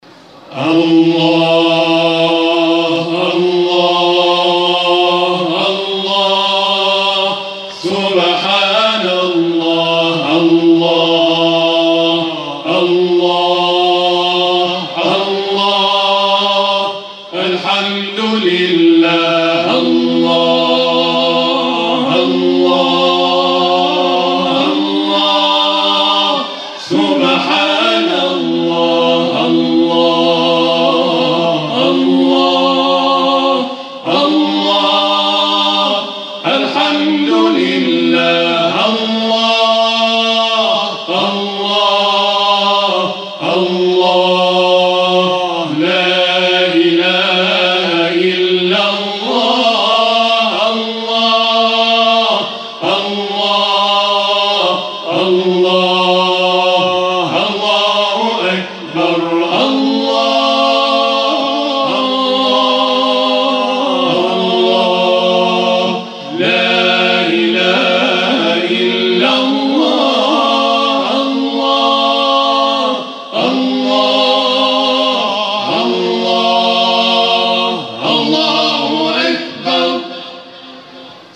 در پایان، اجرای شب گذشته این گروه تواشیح در محفل انس با قرآن کریم ویژه ماه مبارک رمضان در مجتمع فرهنگی سرچشمه ارائه می‌شود.
برچسب ها: گروه تواشیح ، جلسه قرآن ، محفل انس با قرآن ، گروه تواشیح نور